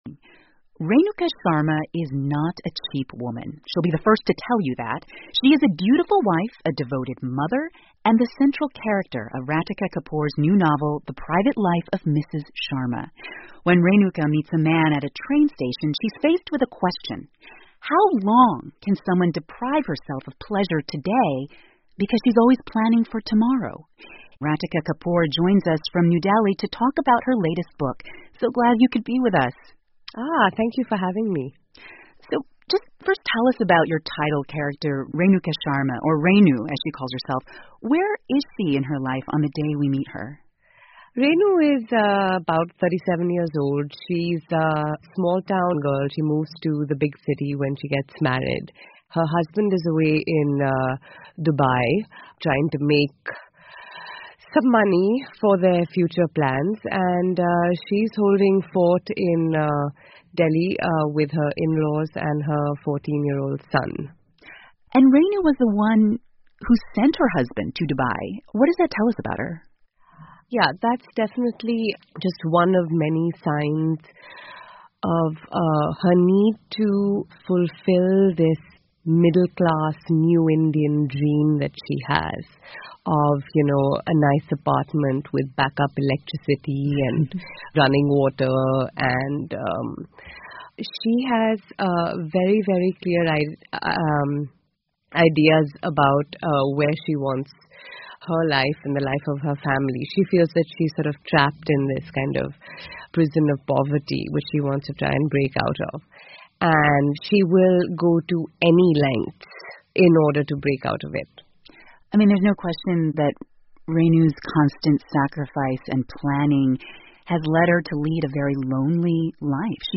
美国国家公共电台 NPR Author Interview: 'The Private Life Of Mrs. Sharma' 听力文件下载—在线英语听力室